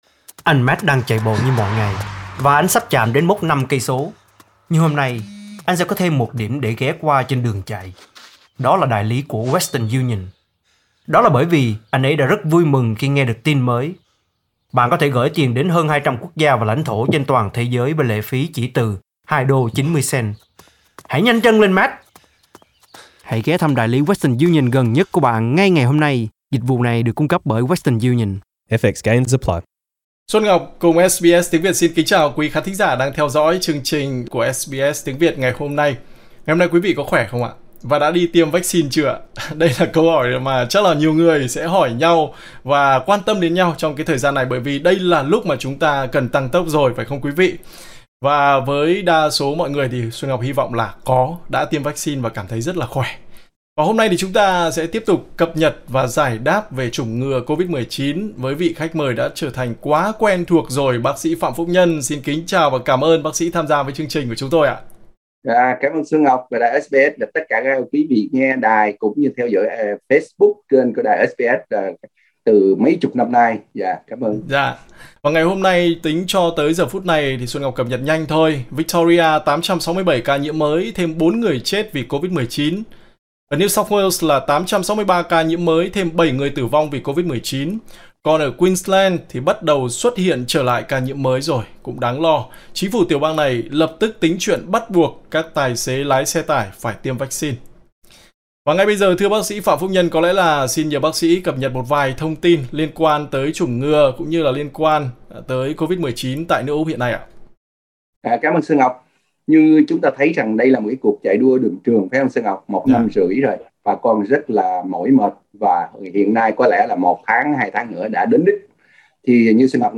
Kính mời quý vị nghe toàn bộ nội dung cuộc phỏng vấn trong phần âm thanh bên trên.